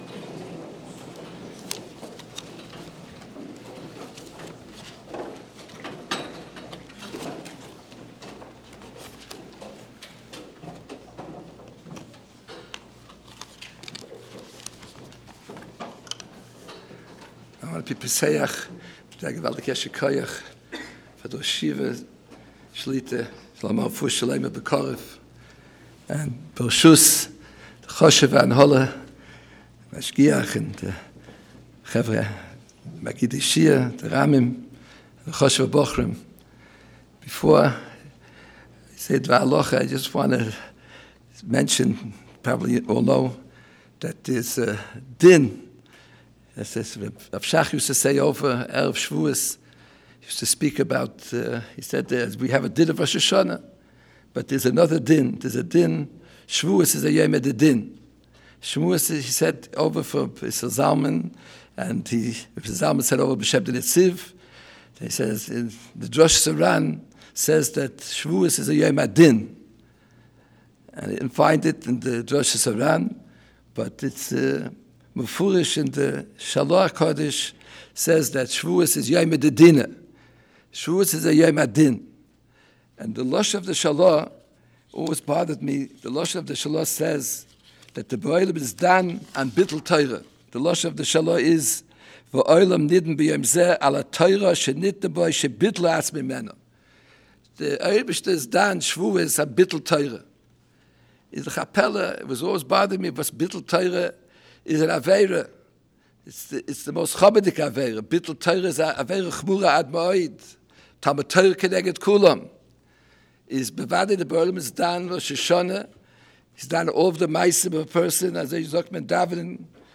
Special Lecture - Ner Israel Rabbinical College